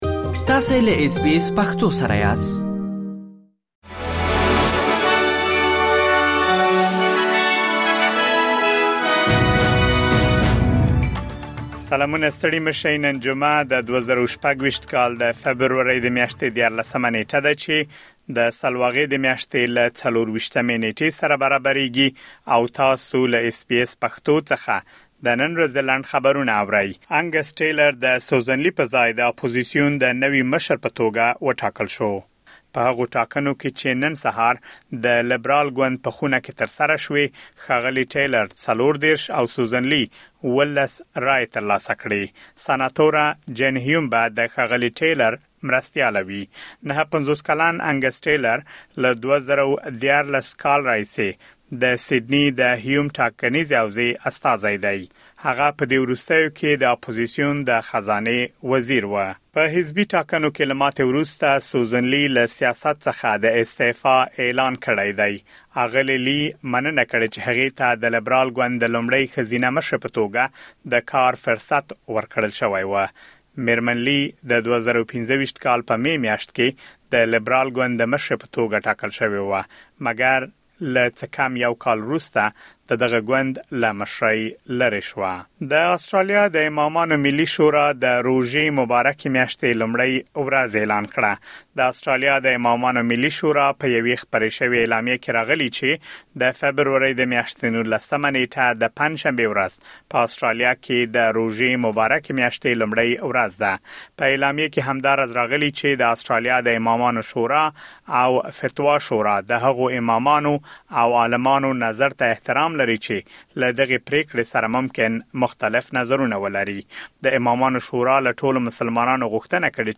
د اس بي اس پښتو د نن ورځې لنډ خبرونه |۱۳ فبروري ۲۰۲۶